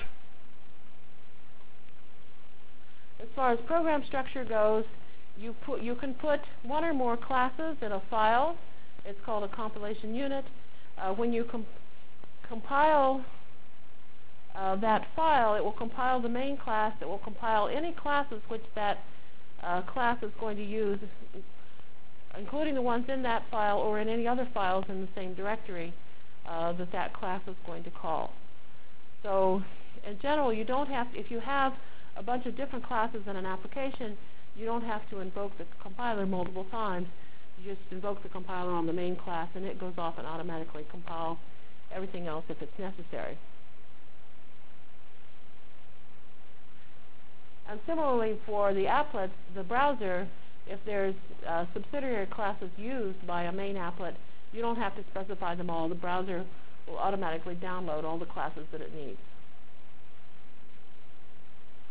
From Jan 22 Delivered Lecture for Course CPS616 -- Java Lecture 1 -- Overview CPS616 spring 1997 -- Jan 22 1997.